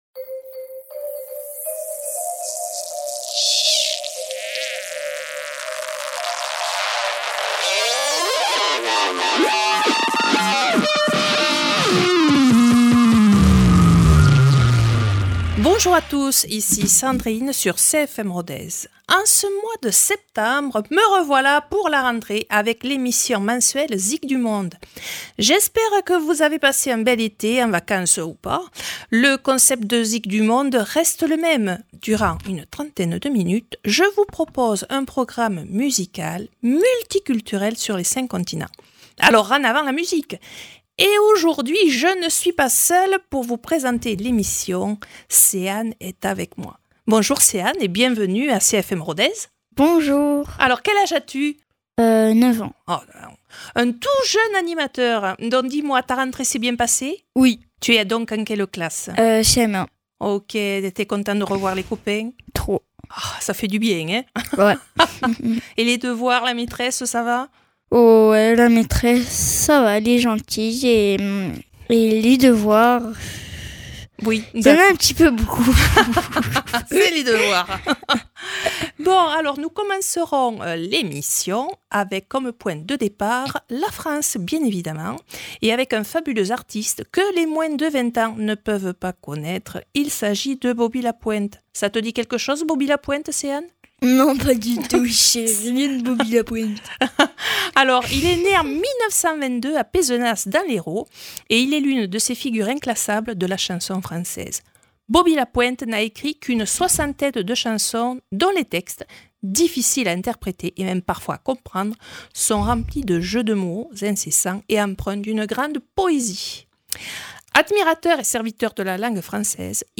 Mais, le moral est bon avec des musiques du monde entier.